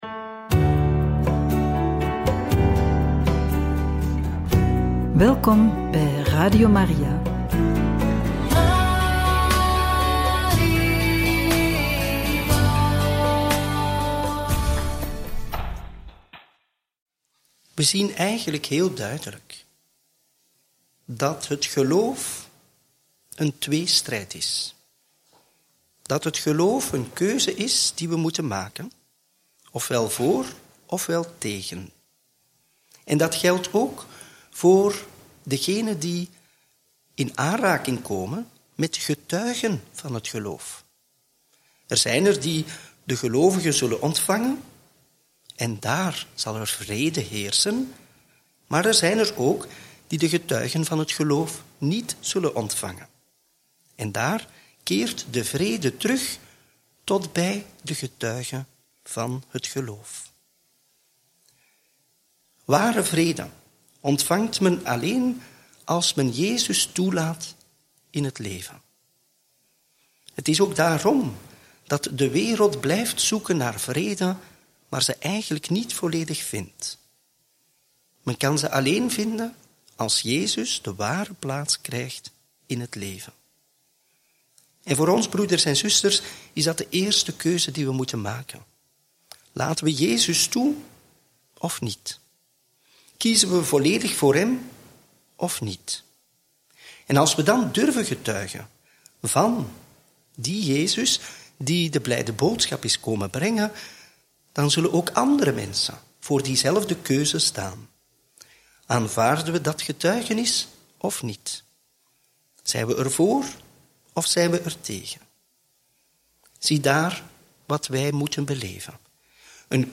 Homilie bij het Evangelie van donderdag 10 juli 2025 – Mt 10, 7-15